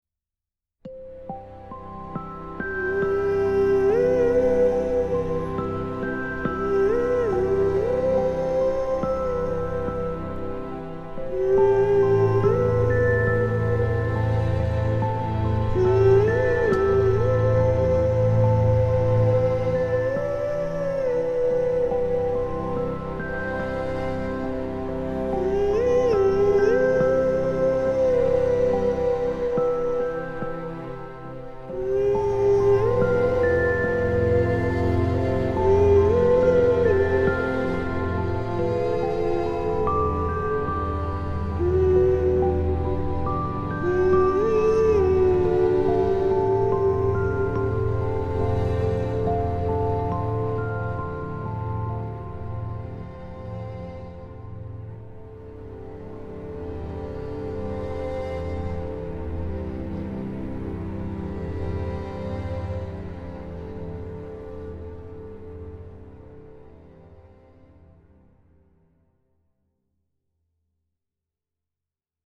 girl voice melody